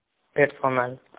Ääntäminen
UK : IPA : /ˈpɜː.sən.əl/ US : IPA : /ˈpɜ˞.sən.əl/